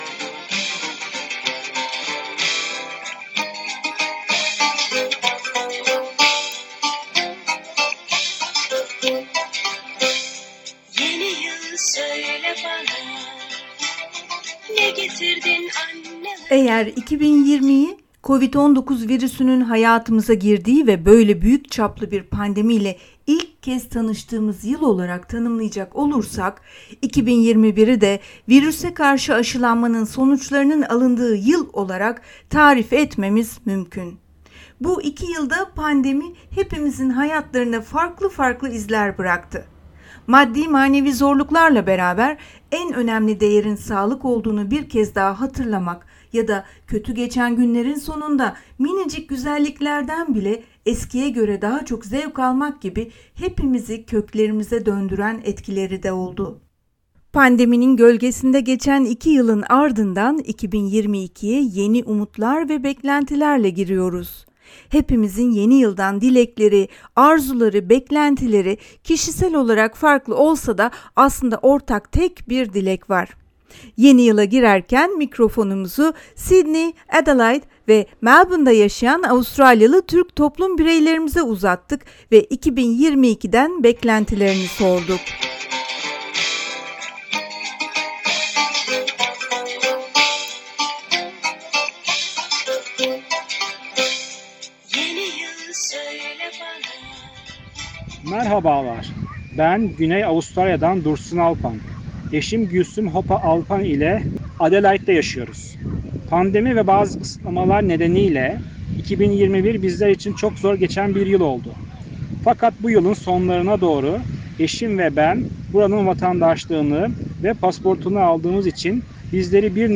Sydney, Adelaide ve Melbourne’da yaşayan Avustralyalı Türk toplum bireylerimiz biten yılı değerlendirdi, 2022'den beklentilerini mikrofonumuza anlattı...